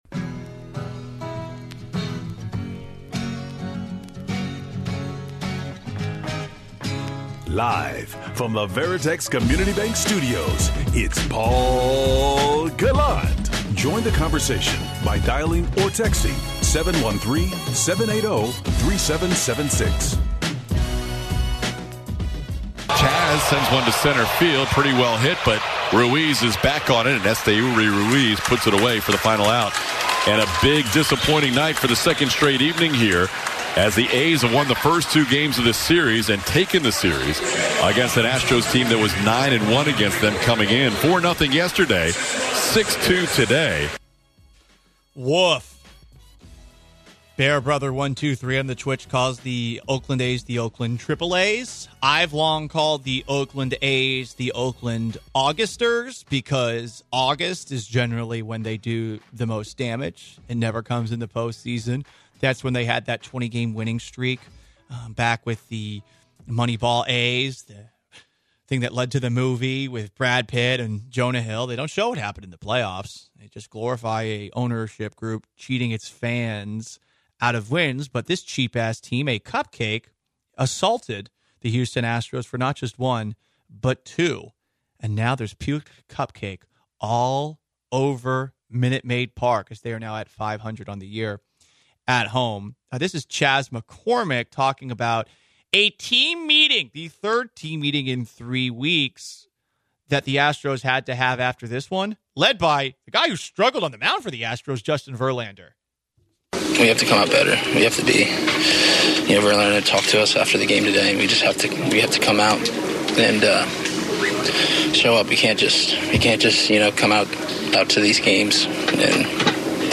with a frequent caller of the show